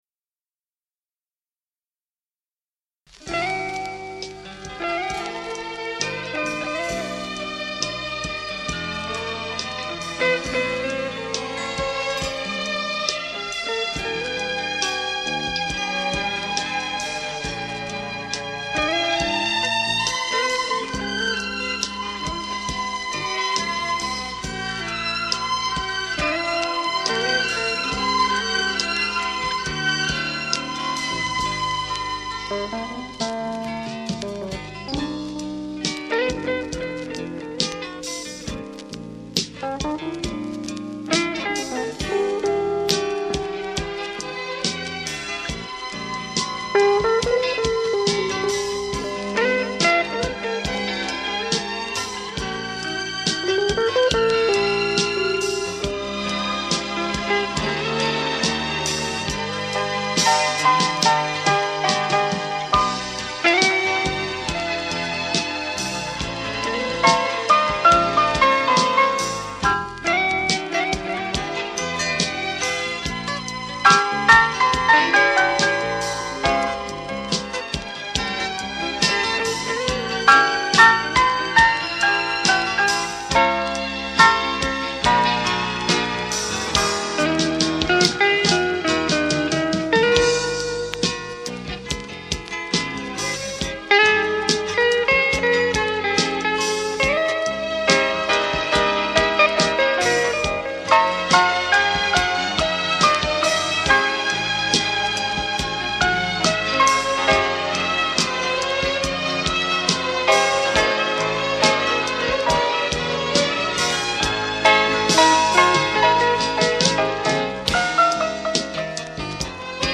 12 violini - 4,viole - 2 violoncelli
1 corno - 1 flauto '.1 oboe
2 trombe - 1 sax contralto - 4 + 4 coro
2 chitarre classiche - 2. chitarre elettriche
1 contrabbasso - 1-batteria